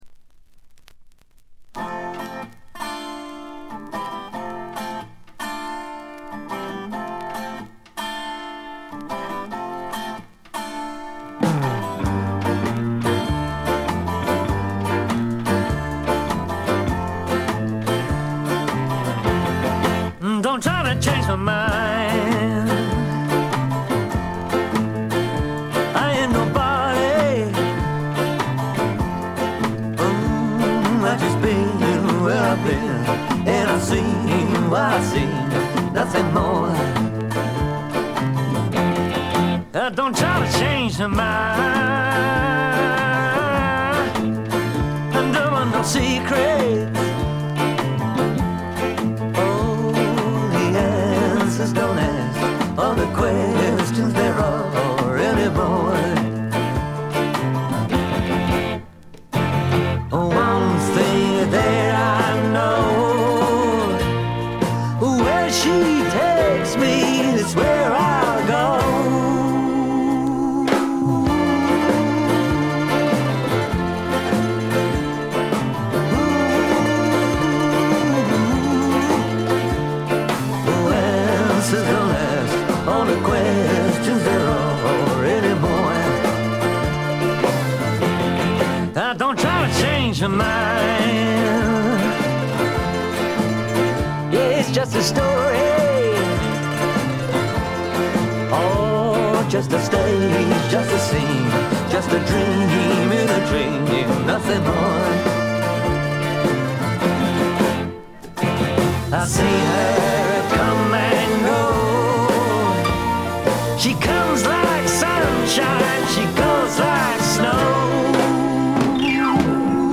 バックグラウンドノイズ、チリプチ多め大きめですが、静音部（特にA3序盤あたり）以外では気にならないレベルと思います。
試聴曲は現品からの取り込み音源です。